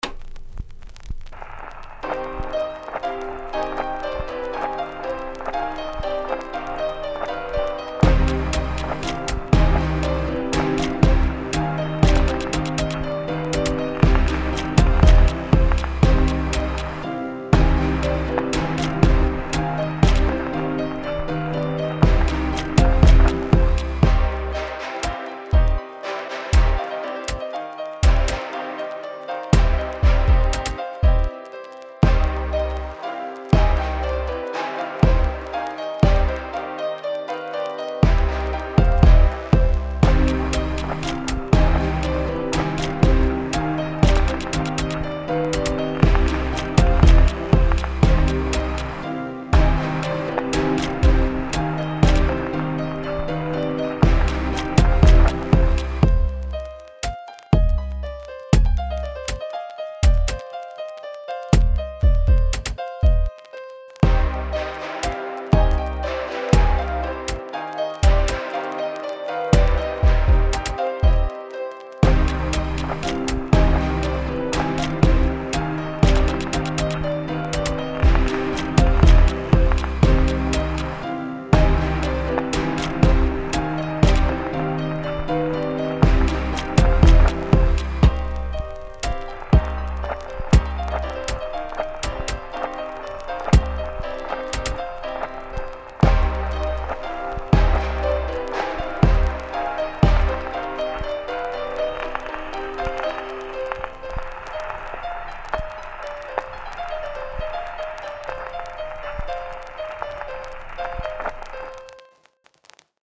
Pop Энергичный 120 BPM